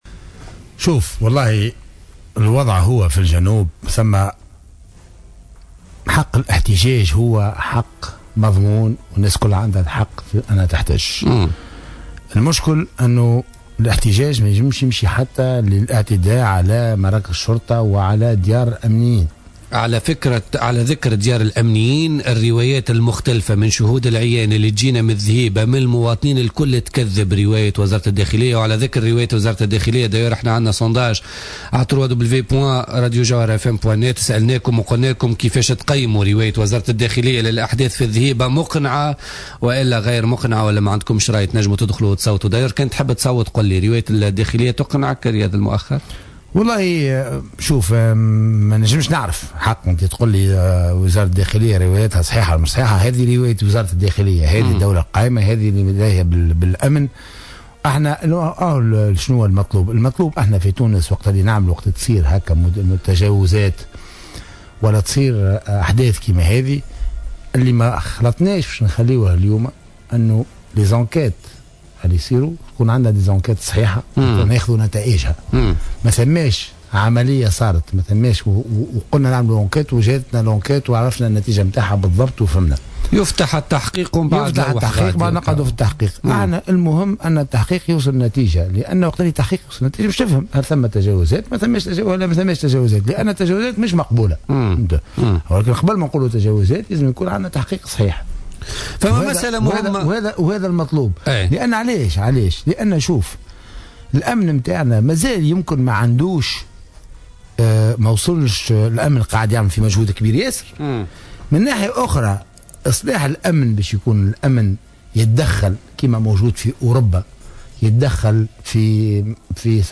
دعا القيادي في حزب آفاق تونس،رياض المؤخر،ضيف برنامج بوليتيكا اليوم الثلاثاء إلى ضرورة فتح تحقيق جدي في أحداث الذهيبة وبن قردان.